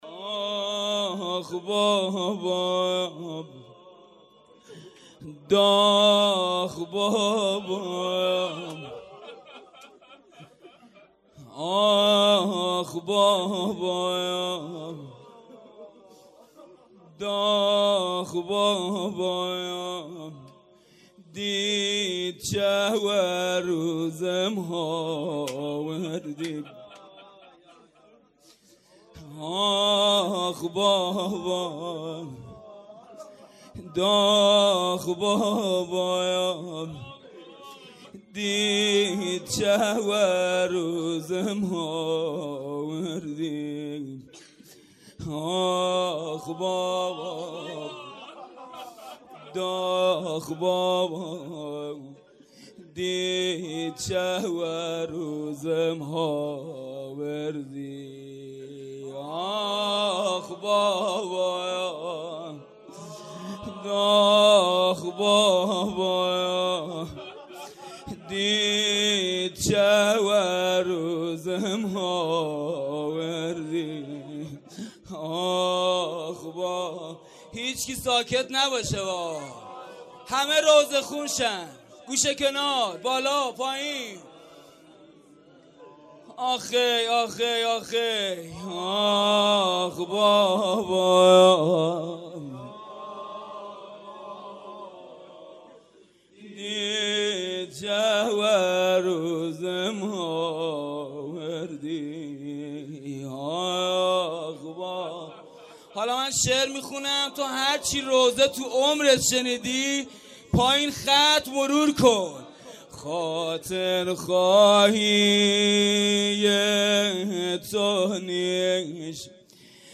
هیئت ذبیح العطشان کرمانشاه